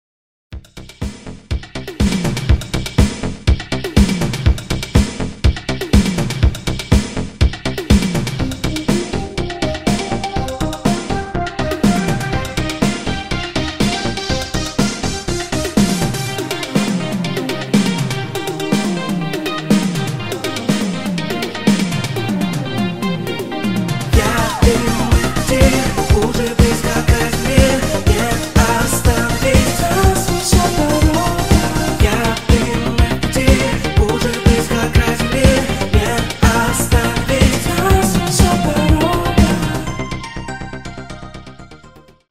• Качество: 320, Stereo
мужской голос
Synth Pop
80-е
Прикольная музычка в стиле 80-ых